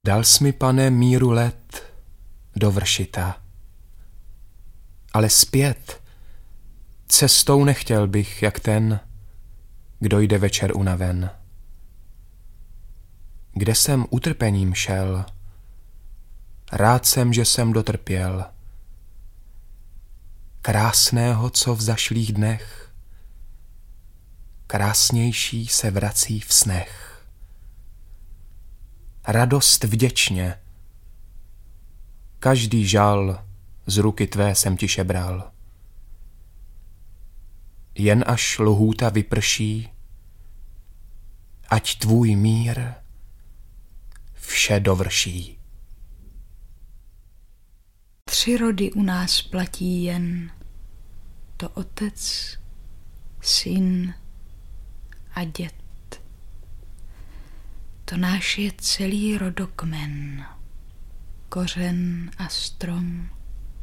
• AudioKniha ke stažení Sládek: Polní cestou. Výběr básní